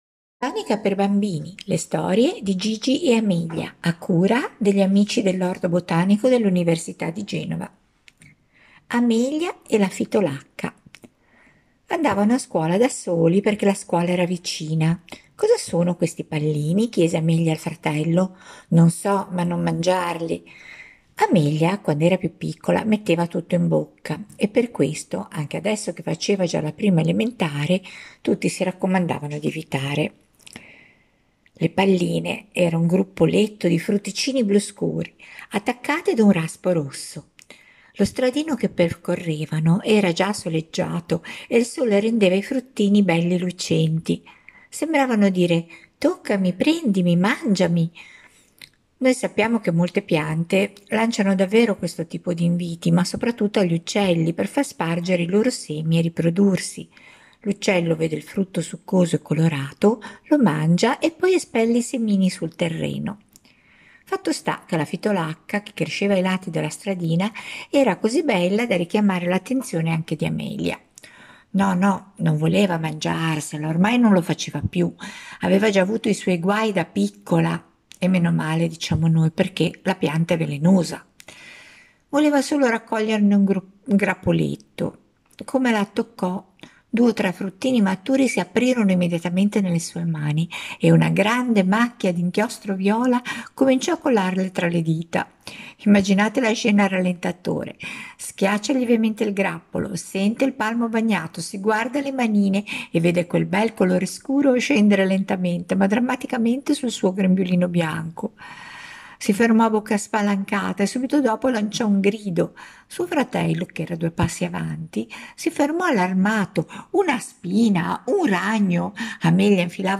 Presentiamo le storie di Gigi e Amelia, due fratellini alla scoperta delle piante, brevi racconti scritti, letti e pubblicati da due volontarie con lo scopo di far conoscere ai bambini le piante che incontrano in città, spesso vicino a casa; alcune sono velenose e devono saperlo fin da piccoli, altre macchiano o pungono, ma tante altre curano e si mangiano!